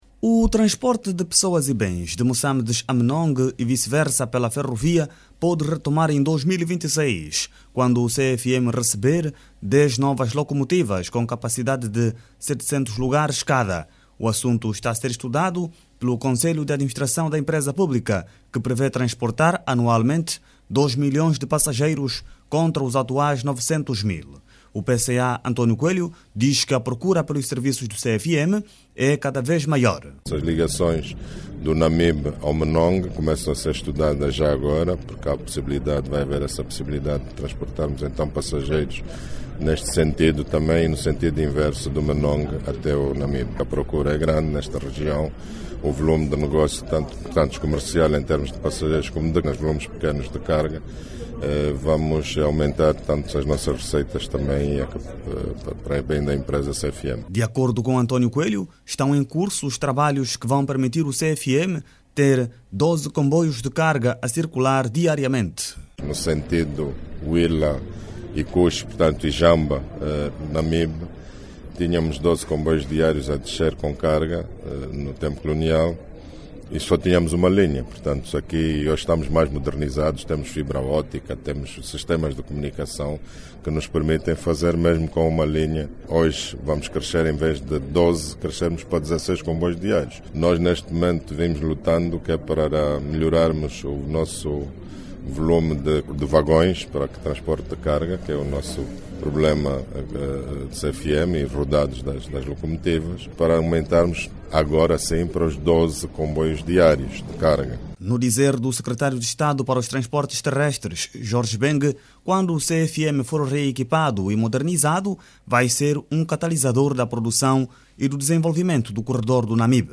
De acordo com as autoridades está prevista a aquisição de 10 novas locomotivas para o CFM, com capacidade de setecentos lugares cada. A reportagem